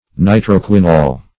Search Result for " nitroquinol" : The Collaborative International Dictionary of English v.0.48: Nitroquinol \Ni`tro*qui"nol\, n. [Nitro- + quine + -ol.]